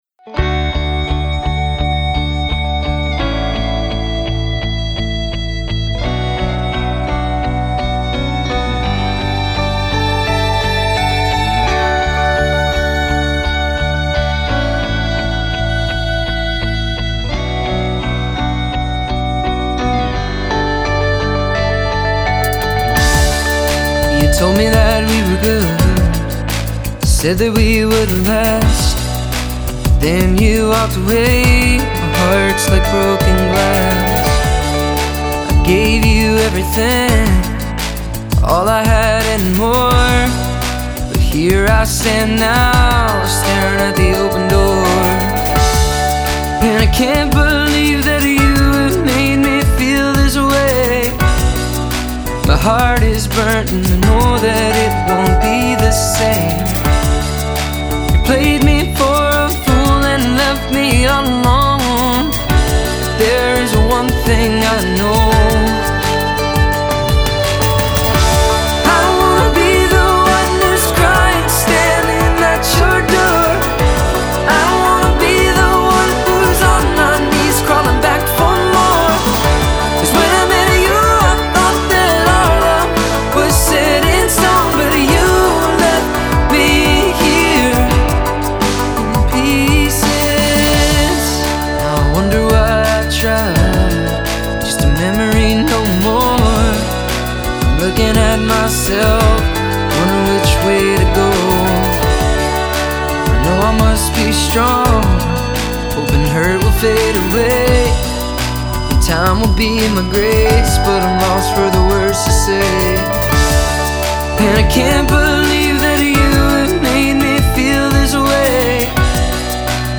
catchy pop lyrics and hooky melodies